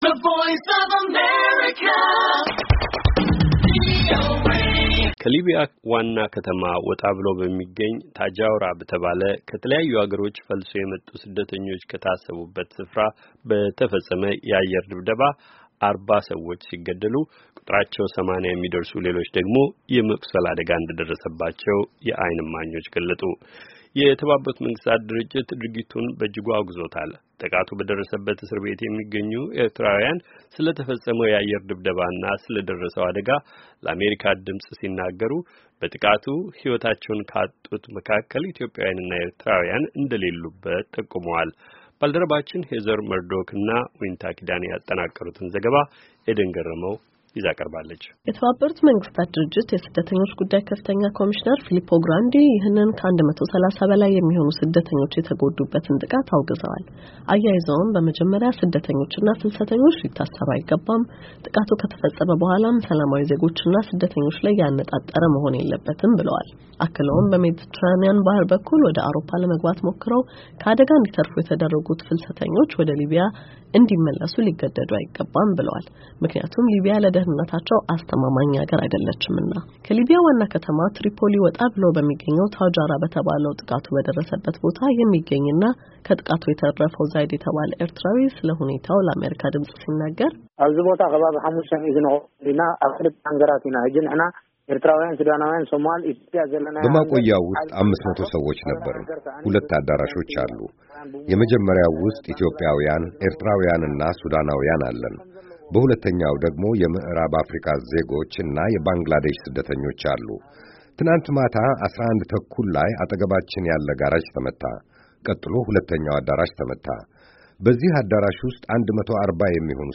ከሊቢያ ዋና ከተማ ትሪፖሊ ወጣ ብሎ በሚገኝ ታጃውራ በተባለ ፍልሰተኞች በታሰሩበት ቦታ በተፈፀመ የአየር ጥቃት እስካሁን 44 ሰዎች መሞታቸው ተረጋግጧል። 130 ሰዎች ደግሞ ቆስለዋል። የተባበሩትን መንግሥታት ድርጅት ድርጊቱን በእጅጉ አውግዞታል። ጥቃቱ በደረሰበት እስር ቤት የሚገኙ ኤርትራውያን ስለ አደጋው ለአሜሪካ ድምፅ ሲናገሩ ፤ በአየር ድብደባው ሕይወታቸውን ካጡት ውስጥ ኢትዮጵያውያንና ኤርትራውያን "የሉበትም" ብለዋል።